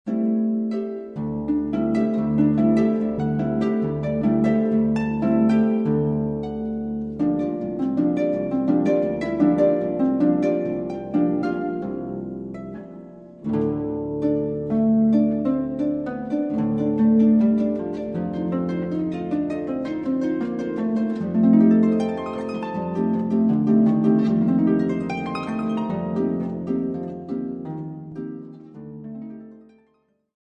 an album of superb Russian solo harp music
modern concert harp